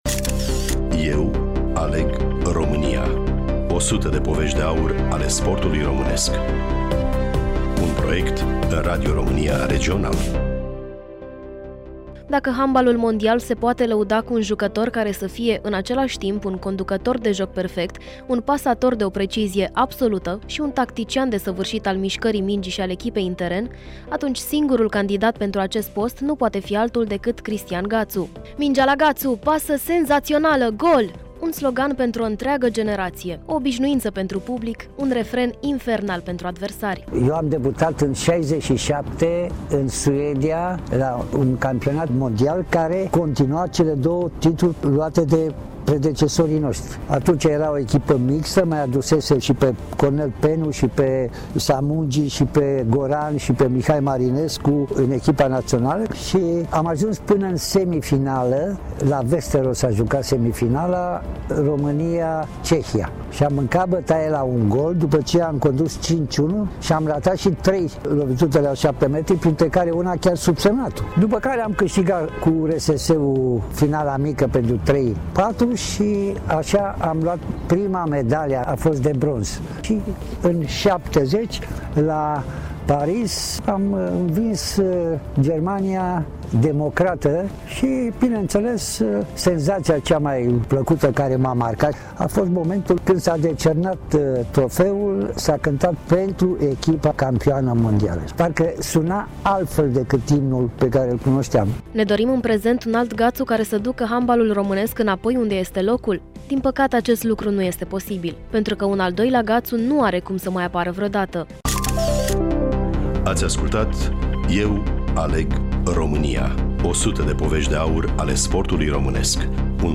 Studioul: Bucuresti FM